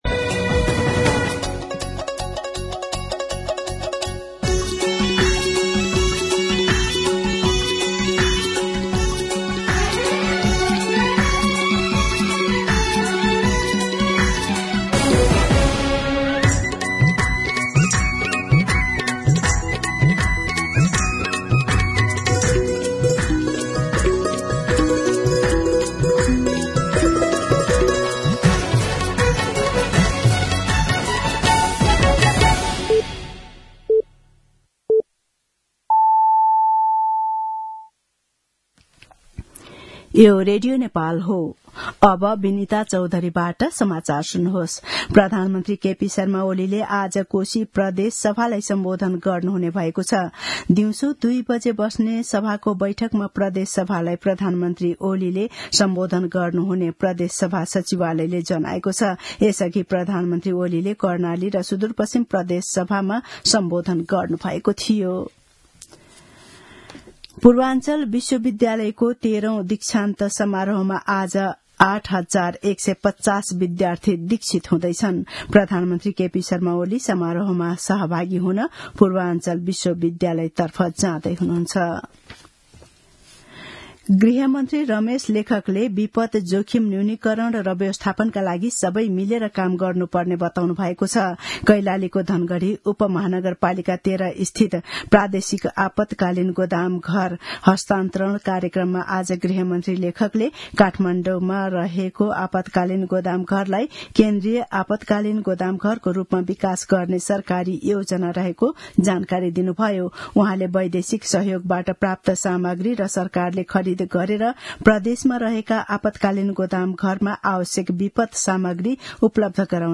दिउँसो १ बजेको नेपाली समाचार : २८ फागुन , २०८१